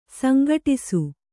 ♪ sangaṭisu